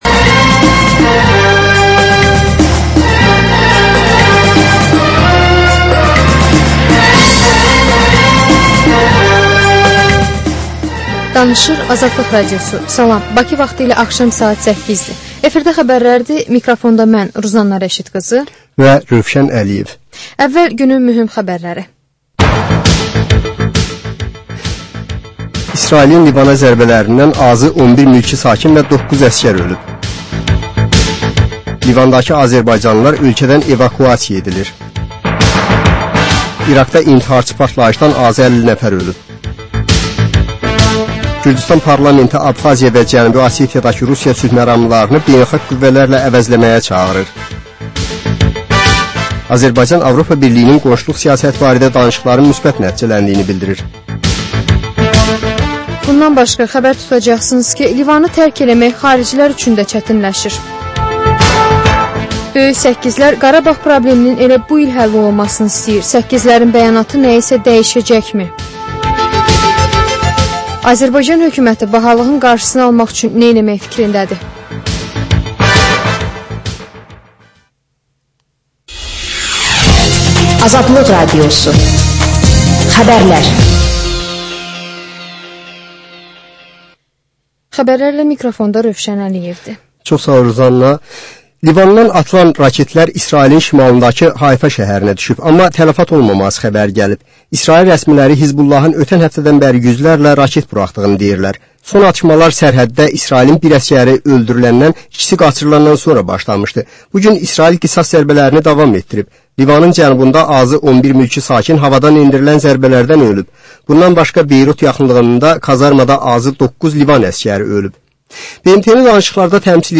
Xəbərlər, reportajlar, müsahibələr.